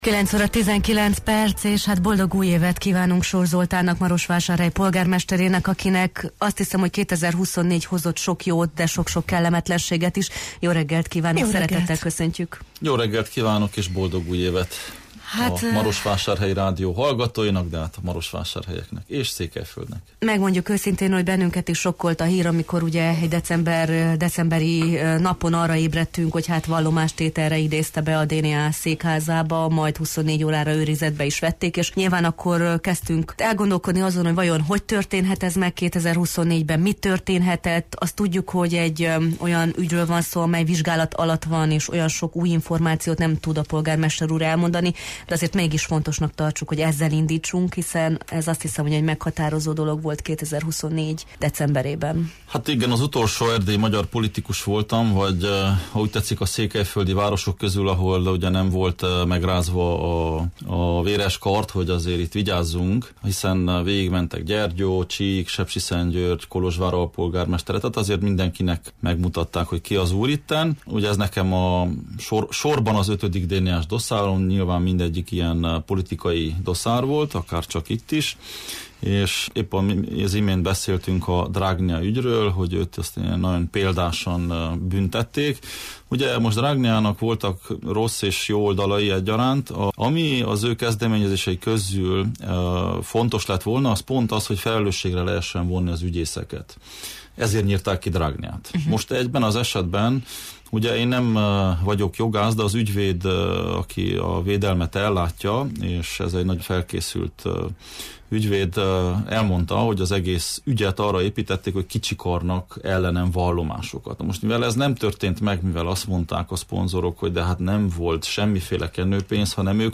Soós Zoltán, Marosvásárhely polgármestere volt a Jó reggelt, Erdély! című műsor vendége. Az elöljáró először járt a stúdiónkban, miután decemberben a korrupcióellenes hatóság tanúként behívta őt a székházába.